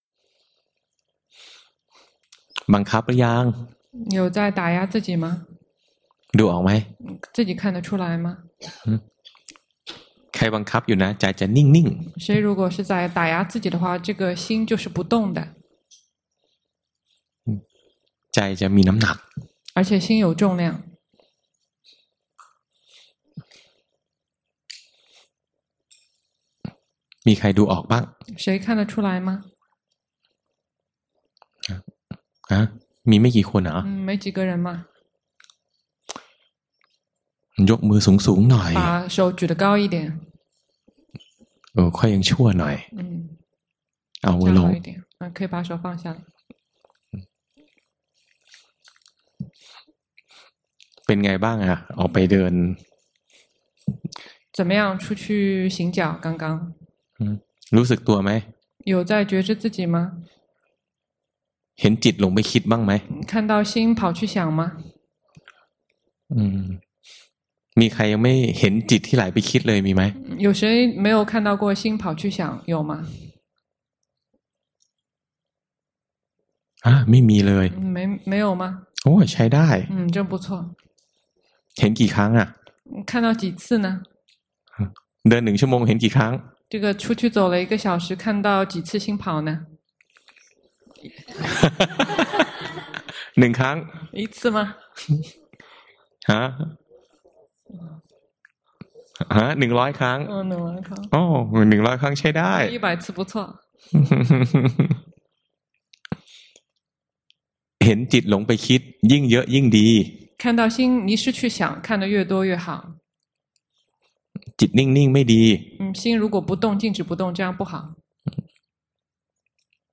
長篇法談｜迷失與緊盯，不禁止但要及時識破 - 靜慮林